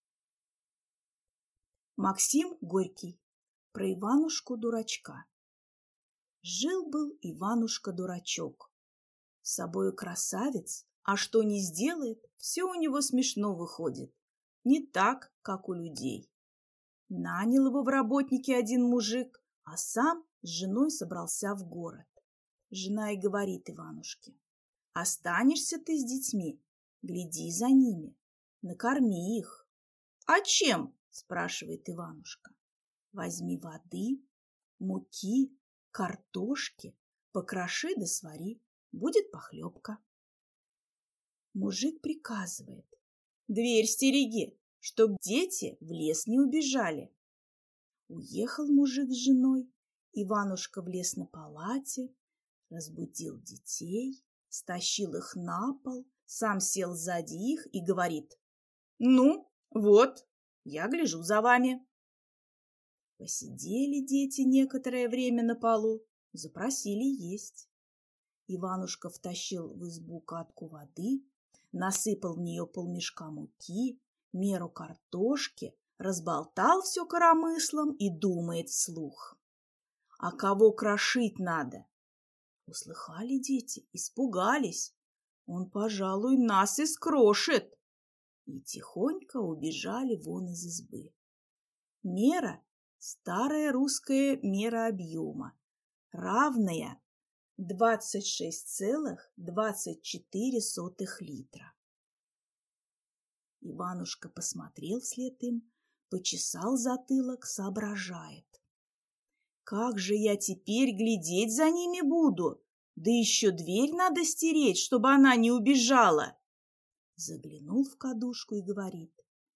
Аудиокнига Про Иванушку-дурачка | Библиотека аудиокниг